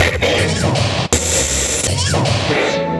80BPM RAD9-R.wav